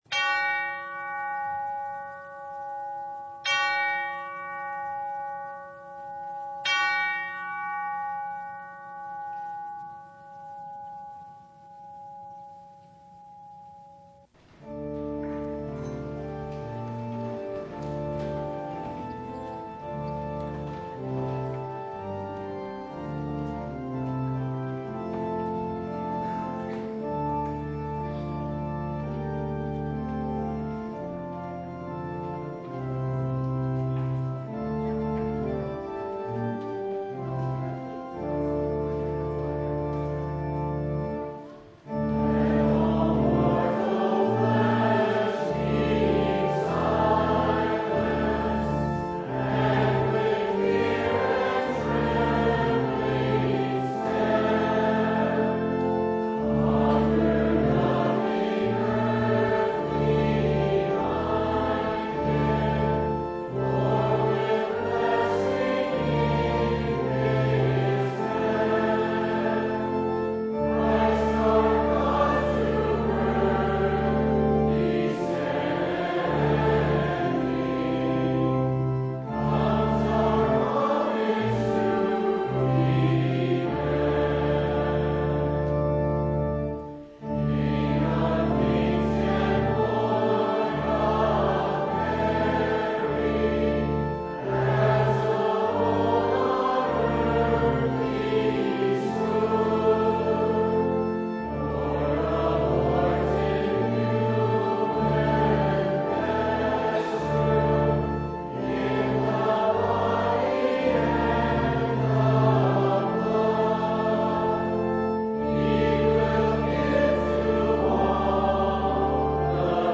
Full Service